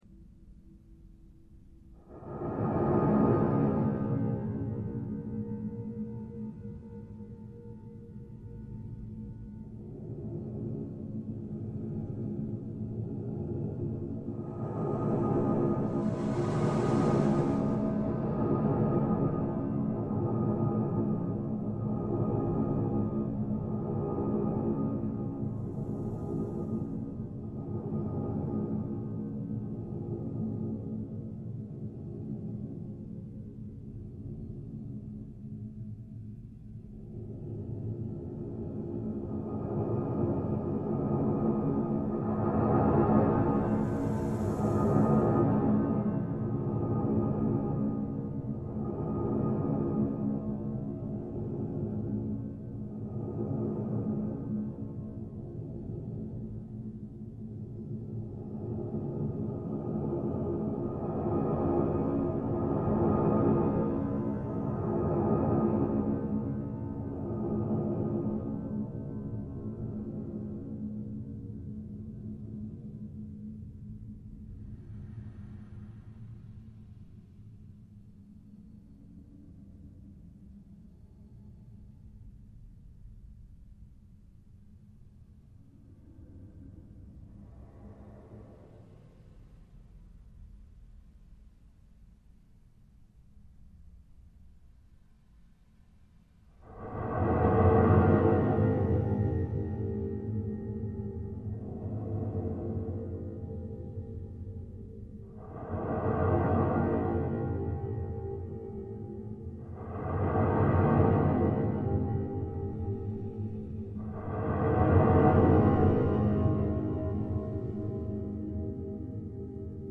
ze_ambience_001.mp3